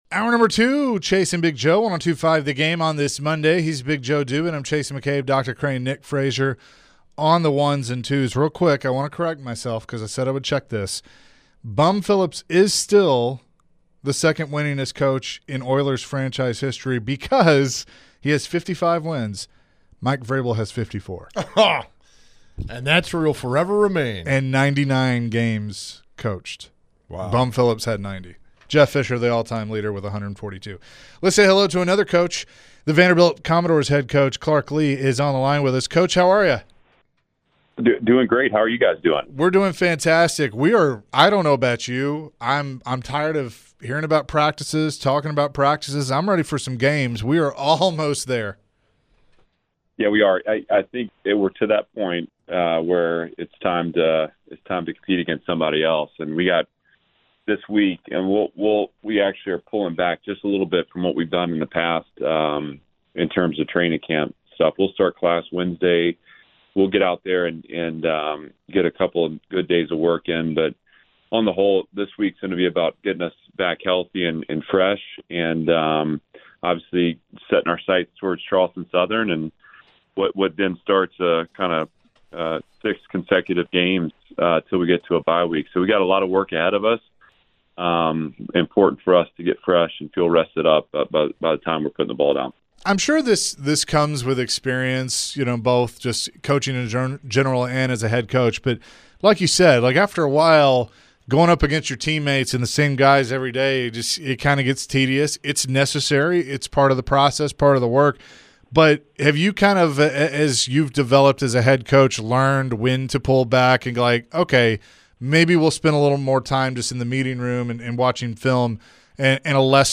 Vanderbilt Head Coach Clark Lea joined the show and shared his thoughts on the upcoming season. Clark also mentioned what he thought about the SEC Docuseries in Netflix.